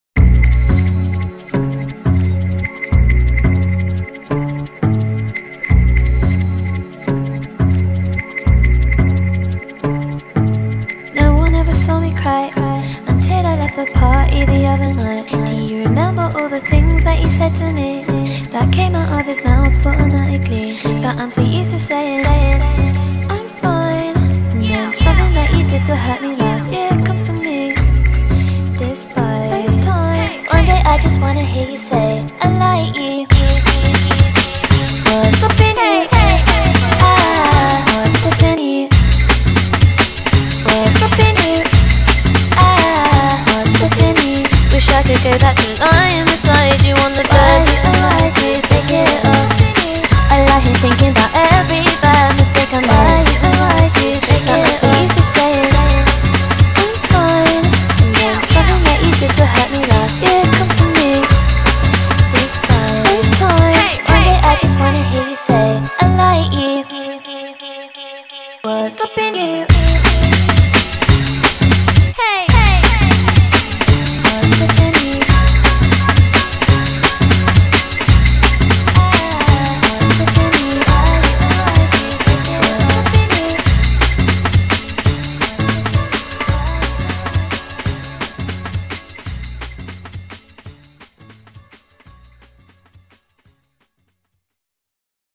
британская певица и продюсер
который совмещает элементы попа и дрилл